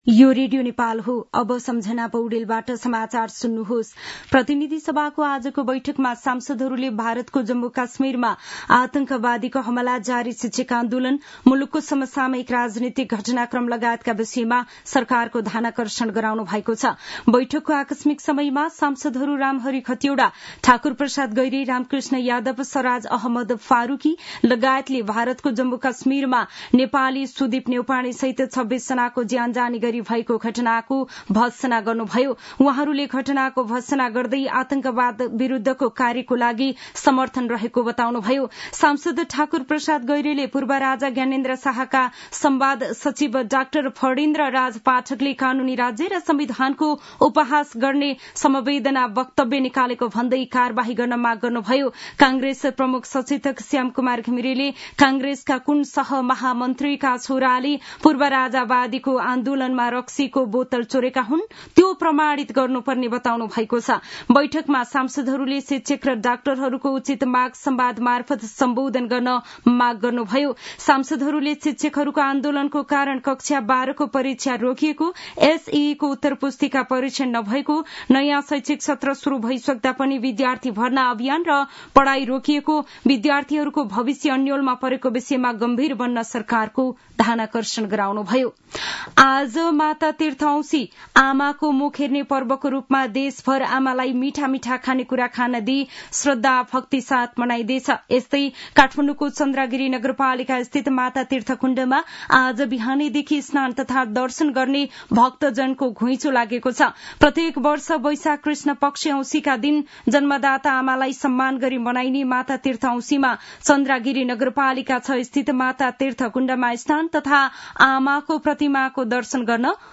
दिउँसो १ बजेको नेपाली समाचार : १४ वैशाख , २०८२
1-pm-news-1-13.mp3